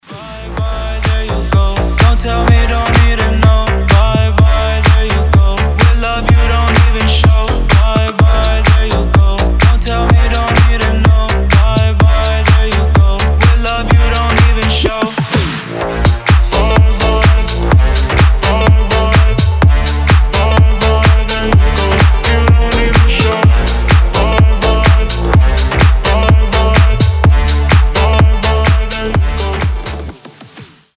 • Качество: 320, Stereo
громкие
энергичные
Стиль: slap house